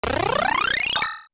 Add sound effects for buff management and notifications; update scene configurations
UI_SFX_Pack_61_26.wav